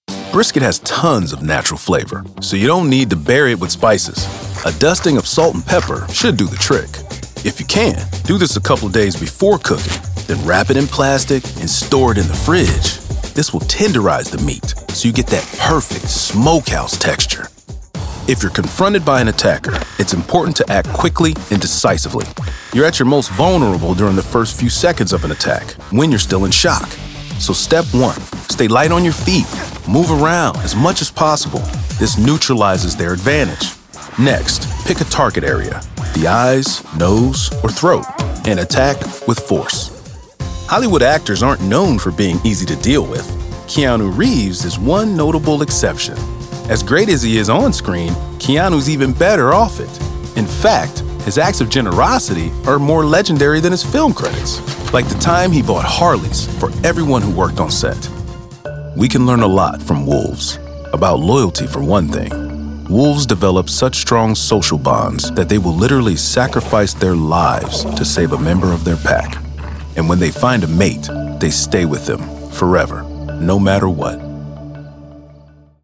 A dynamic, soulful, and soothing voice that is also energetic and engaging.
narration demo
Narration Demo (4).mp3